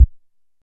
SubImpactSweetener FS048902
Sub Impact Sweeteners; Short And Low Thud Sweetener. - Fight Sweetener